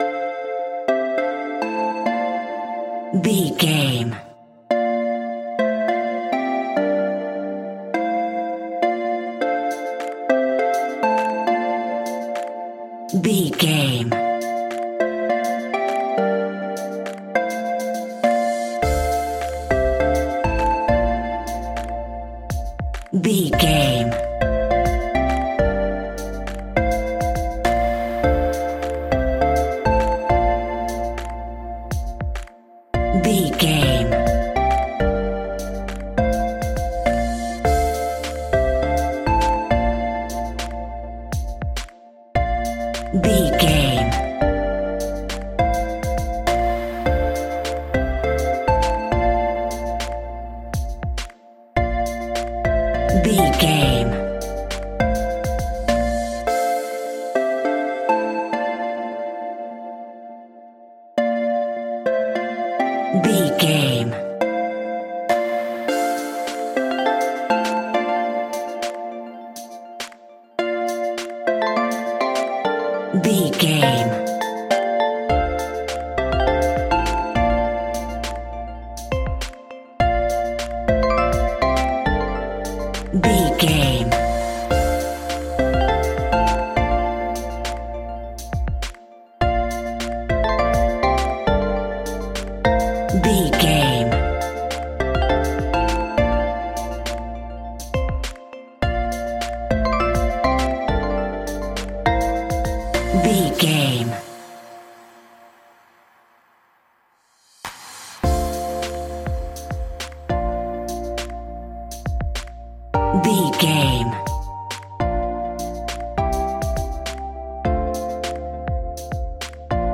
Aeolian/Minor
D
hip hop music
chilled
laid back
hip hop drums
hip hop synths
piano
hip hop pads